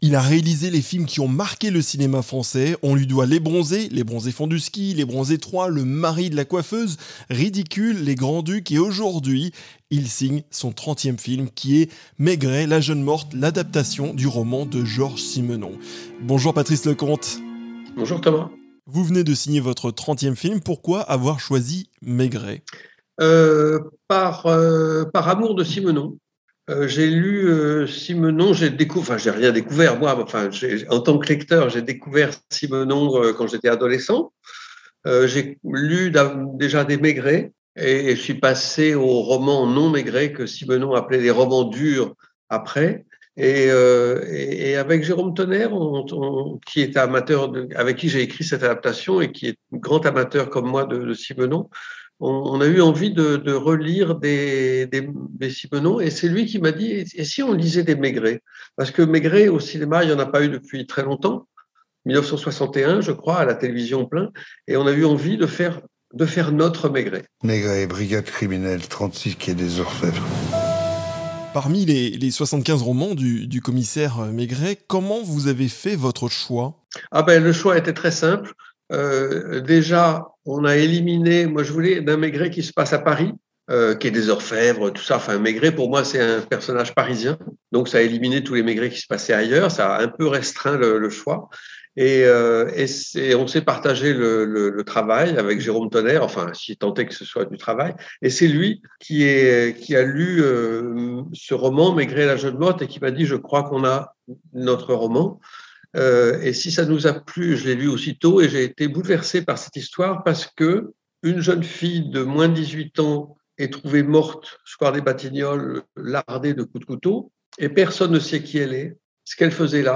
Interview Exclusive de Patrice Leconte
A l'occasion de la sortie de son nouveau film, nous avons reçu Patrice Leconte au micro de SBS.